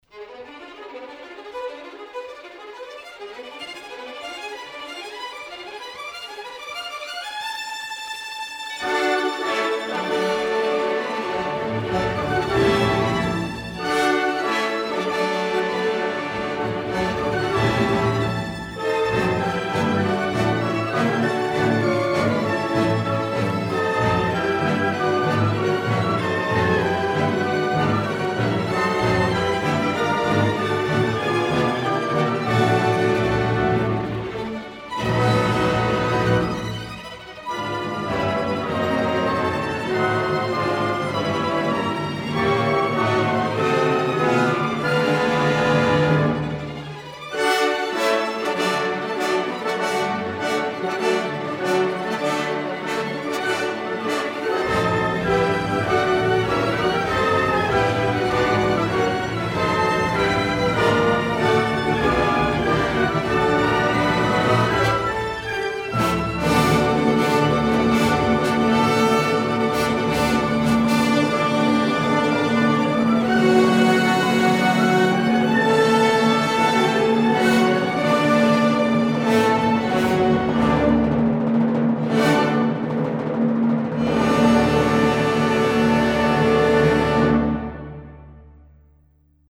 序曲の最後近く、弦楽器だけが面倒くさそうなスケールを演奏するあたりから、
ver1_overture.mp3